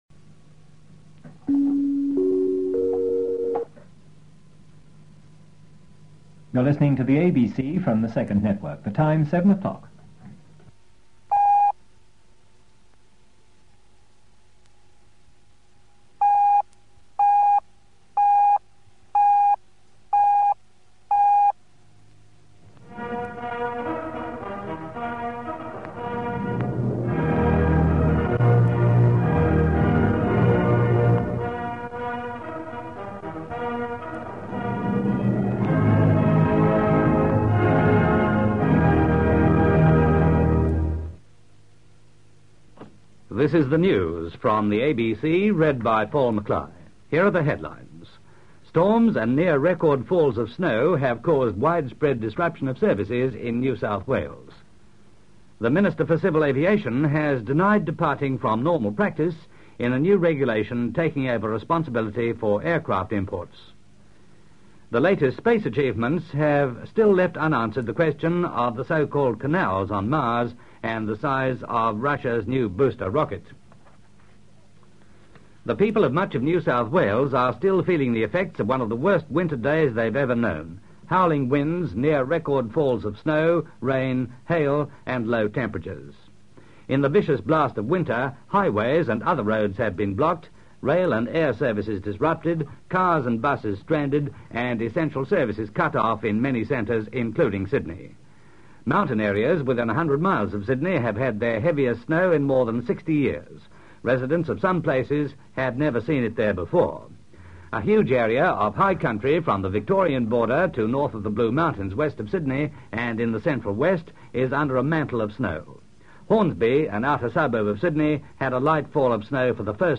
ABC News covering the major July 1965 NSW snowstorm, broadcast at 7pm Sunday 18 July 1965.
The 7pm ABC radio news was the major news bulletin of the day, and the NSW snow story headed both the national and state sections (separated by a "bing" to tell the regional stations to poke their local newsreaders into action.) The broadcast has been edited to remove non-snow stories from the national section -- in the state section, the snowstorm was the only news.